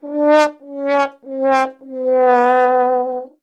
Sad Trombone